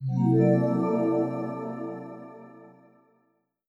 jingle de reveal du vaisseau